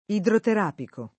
vai all'elenco alfabetico delle voci ingrandisci il carattere 100% rimpicciolisci il carattere stampa invia tramite posta elettronica codividi su Facebook idroterapico [ idroter # piko ] o idroterapeutico [ idroterap $ utiko ] agg. (med.); pl. m. -ci